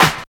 35 SNARE.wav